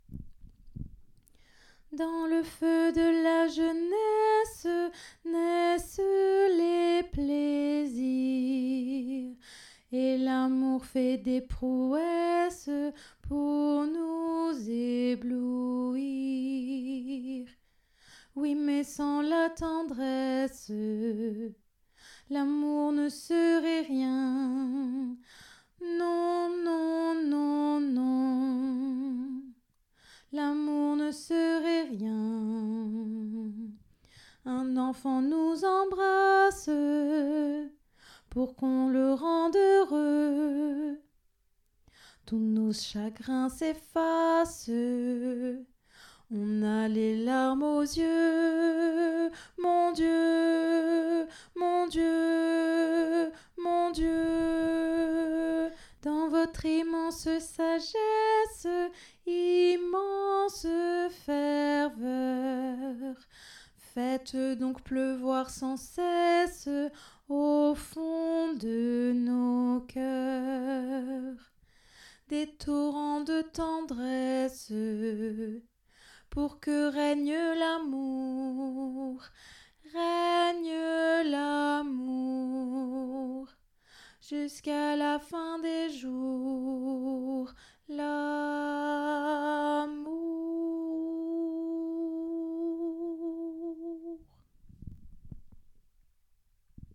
Télécharger la tendresse mesure29-fin alto
ob_8ac6aa_la-tendresse-mesure29-fin-alto.mp3